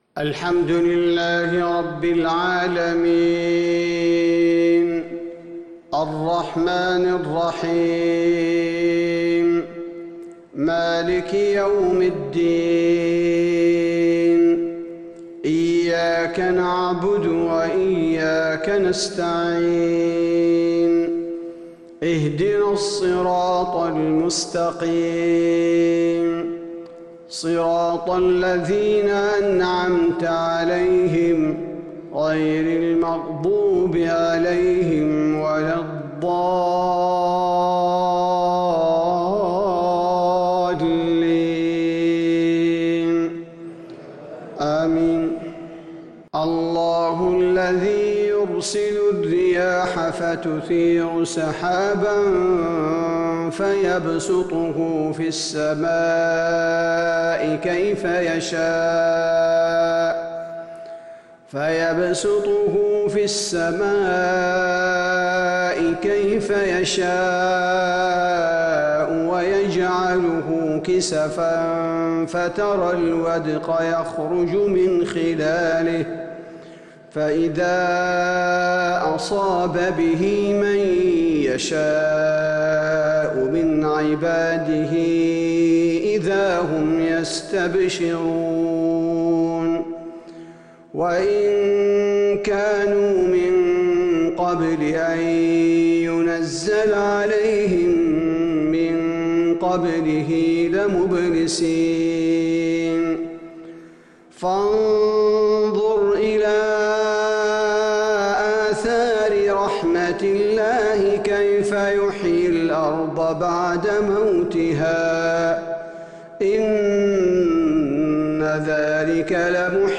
صلاة العشاء للقارئ عبدالباري الثبيتي 6 ربيع الآخر 1442 هـ
تِلَاوَات الْحَرَمَيْن .